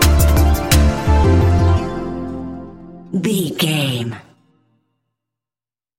Ionian/Major
electronic
techno
trance
synths
synthwave
instrumentals